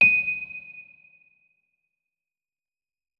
electric_piano